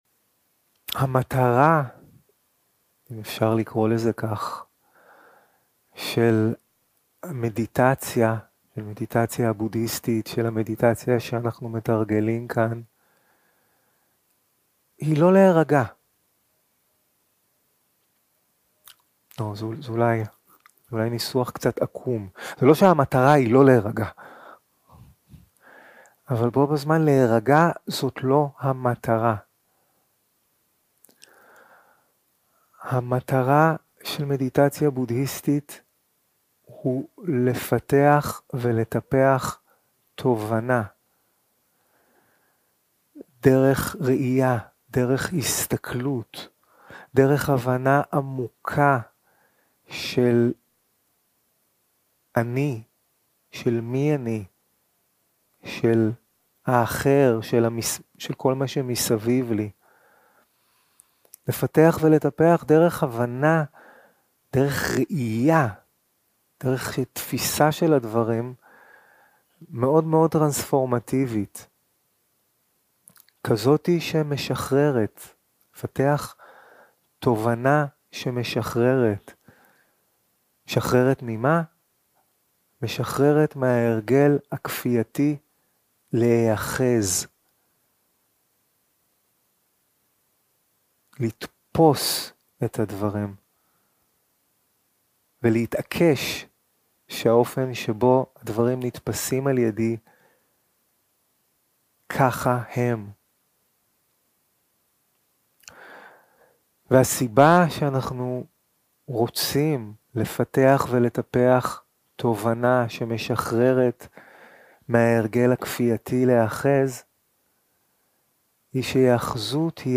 יום 2 – הקלטה 2 – בוקר – הנחיות למדיטציה – נשימה כאובייקט מדיטציה בהליכה + תנוחת ישיבה 0:00 0:00 סוג ההקלטה: Dharma type: Guided meditation שפת ההקלטה: Dharma talk language: Hebrew